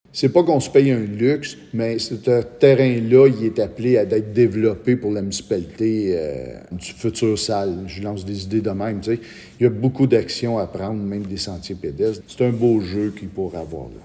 Le maire estime que cet achat permettrait l’aménagement du nouveau garage municipal, mais qu’il offre aussi des possibilités de développement intéressantes dans le futur, comme l’indique le maire, Steve Lefebvre :